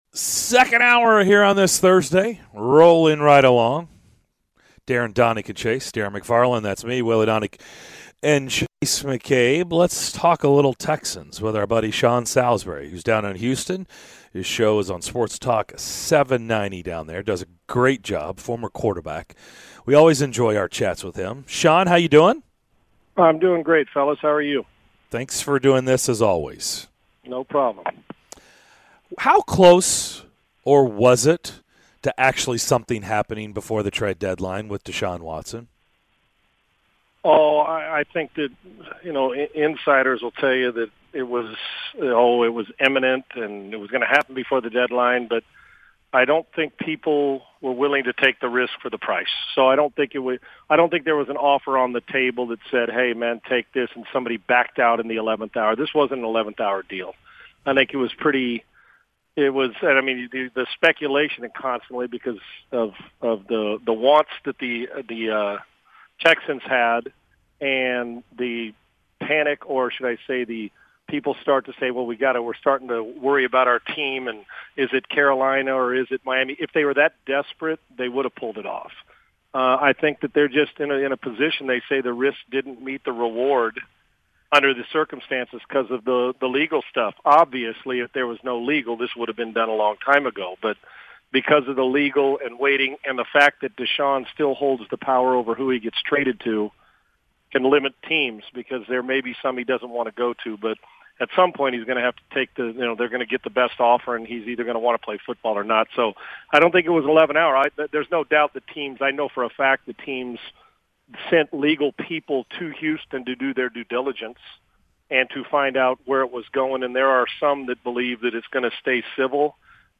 NFL Analyst Sean Salisbury joined the DDC to give his thoughts on the Texans and Titans as well as other story lines from around the league!